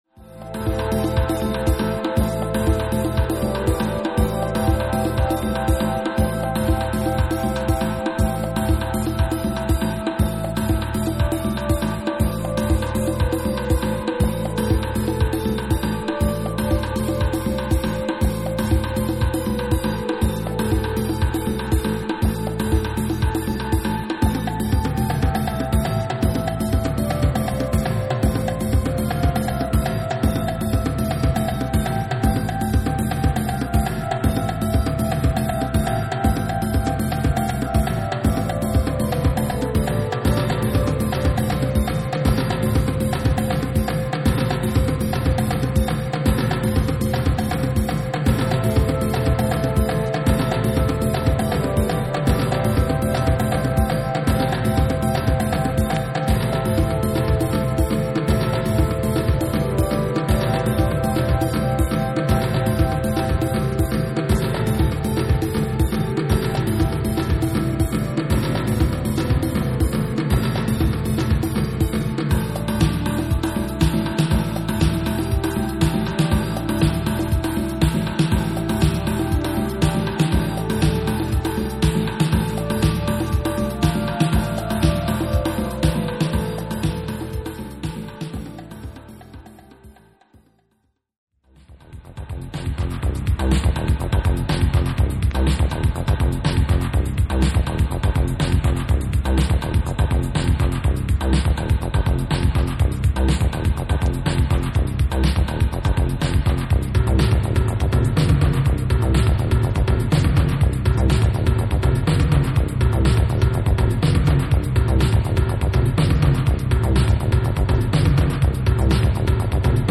リリカルで壮麗なシンセ・ワークに抜群のドラム･トラックが絡むディープ・ハウス・クラシック